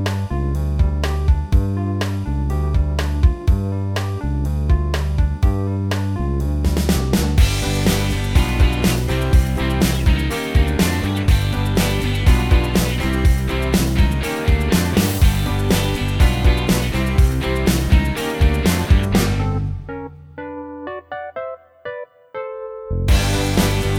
Minus Main Guitar Pop (1990s) 3:00 Buy £1.50